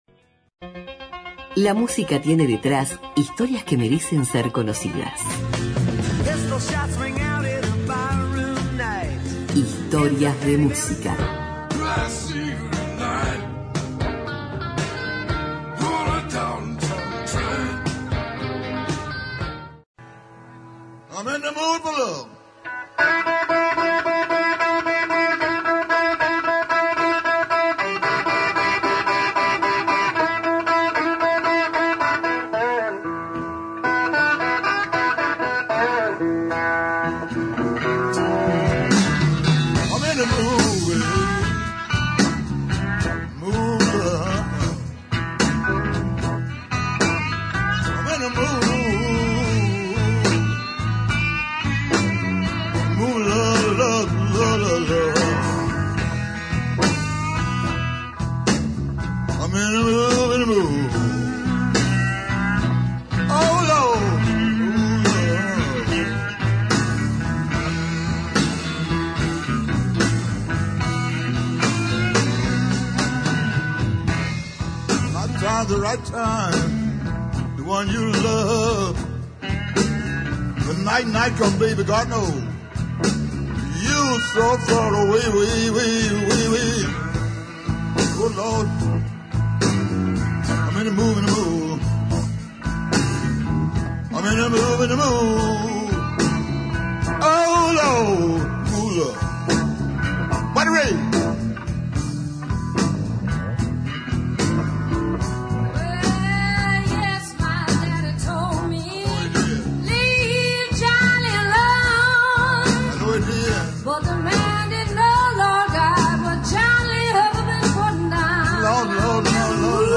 Las canciones de una gran cantante y guitarrista blusera